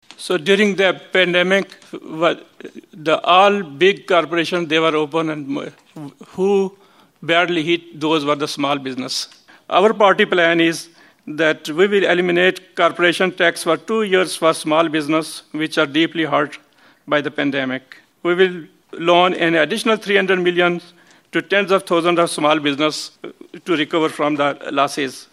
The Haldimand-Norfolk candidates spoke on the topic at the Royal Canadian Legion in Simcoe on Thursday night.